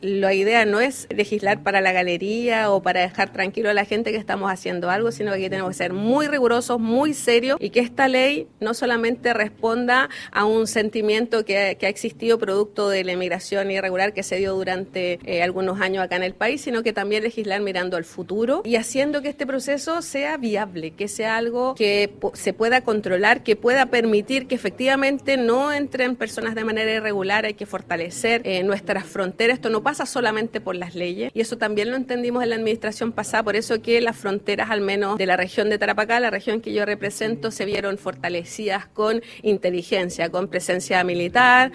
La Comisión de Gobierno Interior del Senado continuó este lunes la discusión del proyecto que modifica la Ley de Migraciones para tipificar el ingreso clandestino al territorio nacional como delito.
La presidenta de la instancia, Danisa Astudillo, señaló que existe división al interior del Senado respecto de convertir el ingreso irregular en delito y advirtió que se requiere un análisis detallado antes de avanzar.